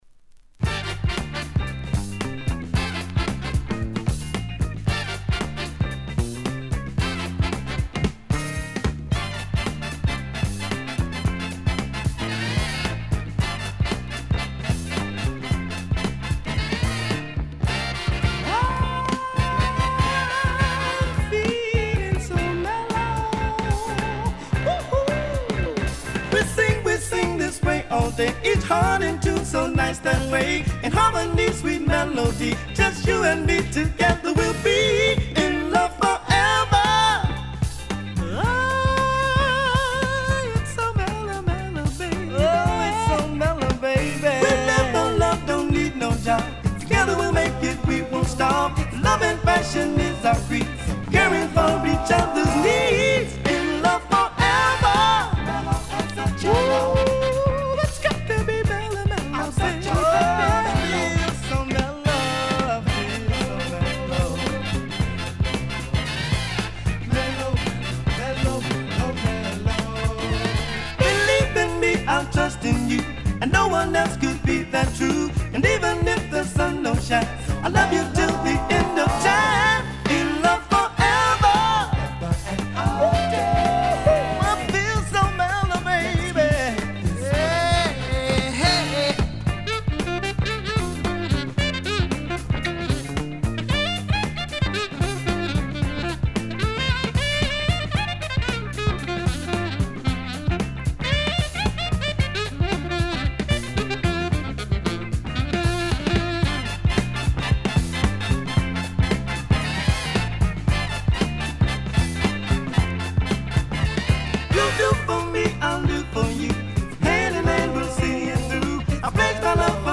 Soul ソウルグループ
再生状態は小さなノイズをわずかな個所で感じる程度で再生良好です。
Side A→Side B(2:44～) 試聴はここをクリック ※実物の試聴音源を再生状態の目安にお役立てください。